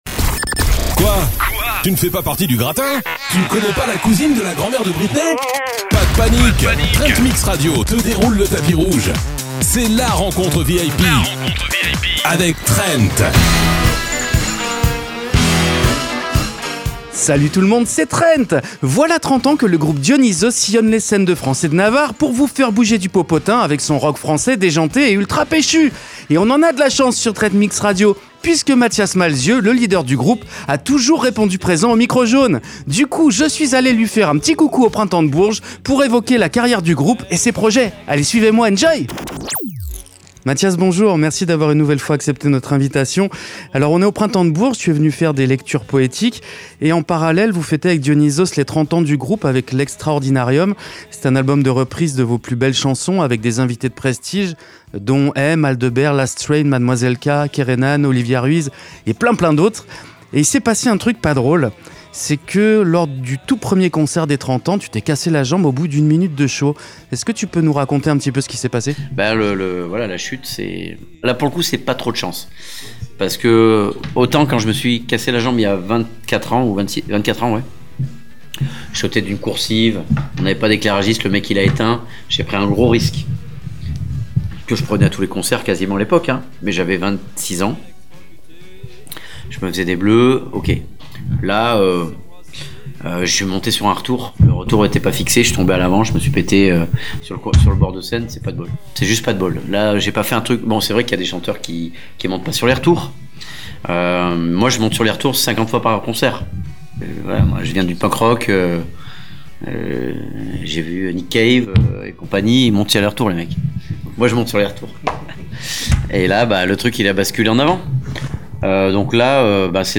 Mathias Malzieu (Dionysos) : l'interview !
Et on a de la chance sur TrenTMix Radio, puisque Mathias Malzieu, le leader du groupe, a toujours répondu présent au Micro Jaune. Du coup, on est allé lui faire un coucou au Printemps de Bourges pour évoquer la carrière du groupe et ses projets.